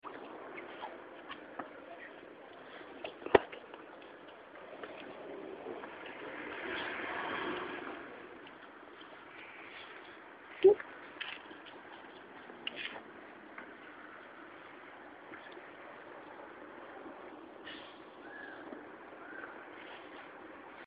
ambiance limoges G.Péri 2011